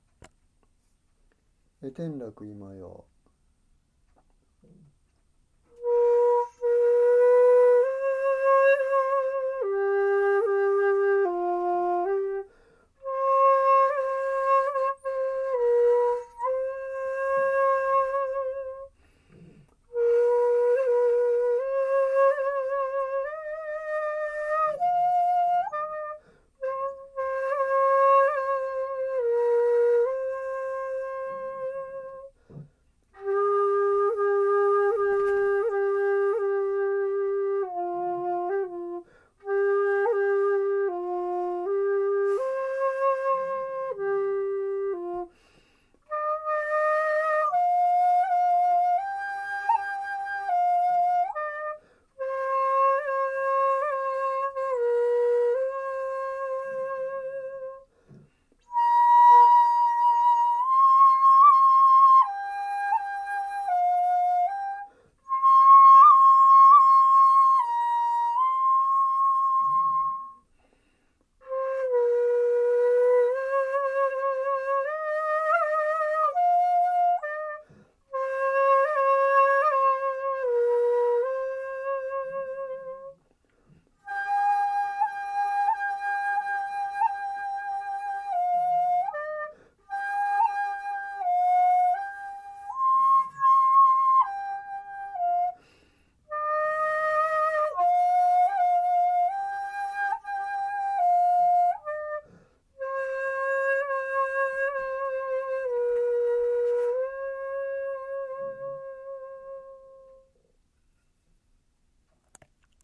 平均で筒音F（勝絶、尺八のツ音）はFとF♯の中間でした。
何とB♭音（下の3つの孔を開ける）を主音（宮）とした古代中国の七声の音階なのです。